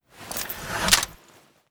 blade2hand_sheathe_001.wav